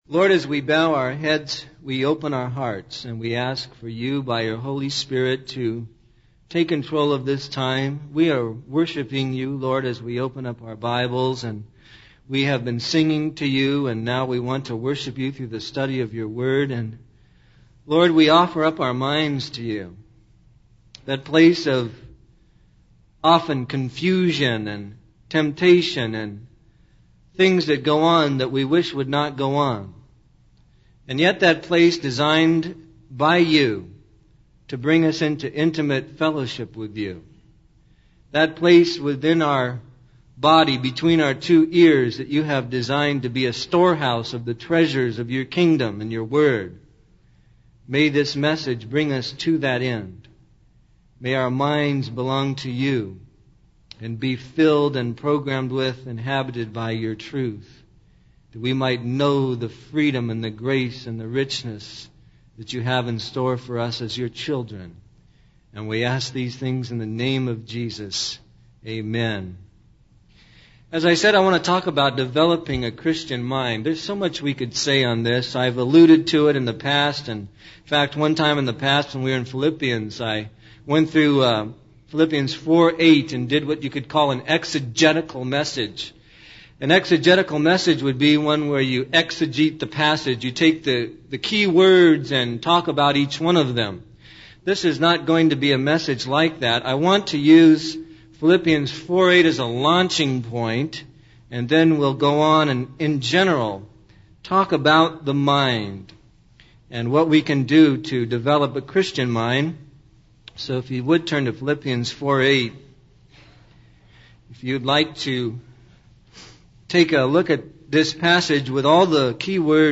In this sermon, the speaker addresses the short attention span of viewers and how it affects their ability to engage with biblical sermons. He emphasizes the negative impact of television on linguistic abilities and encourages viewers to develop a Christian mind. The speaker suggests that a key way to do this is through continual immersion in the Word of God.